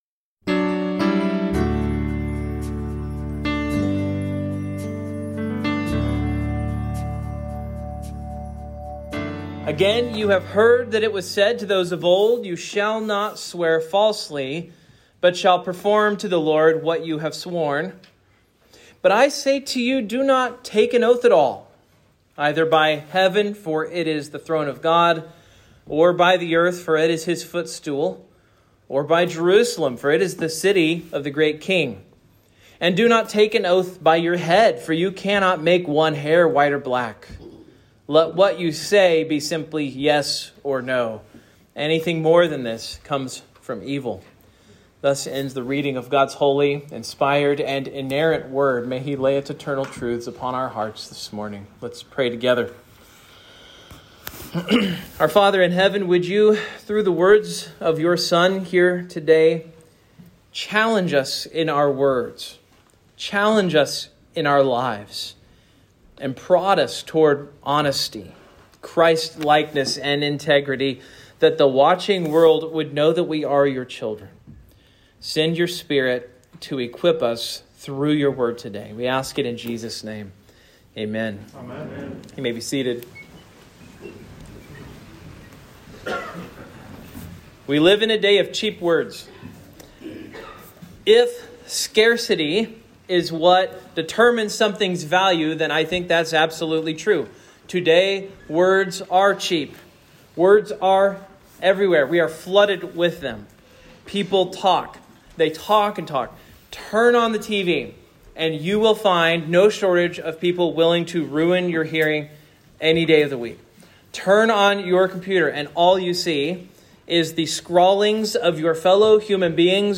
That has been remedied–this post contains the complete sermon.
Matthew 5:33-37 Service Type: Morning PLEASE NOTE